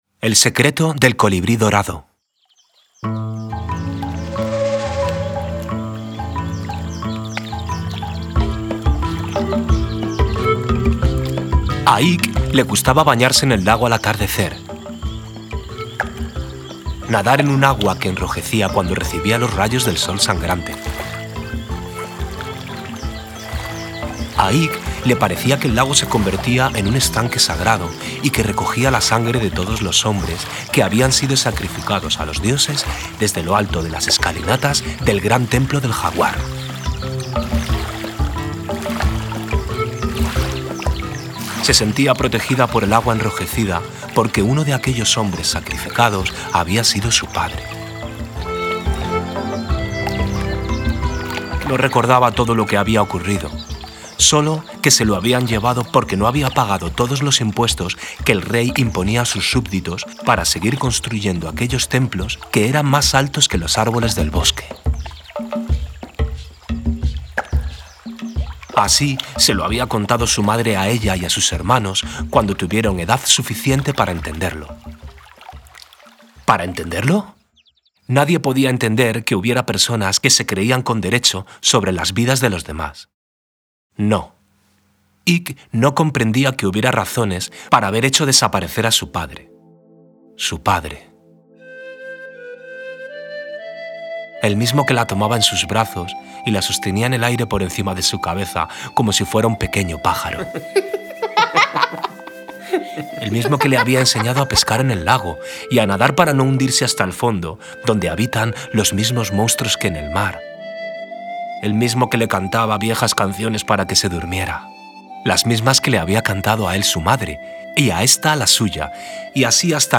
Lectura inicial. El secreto del colibrí dorado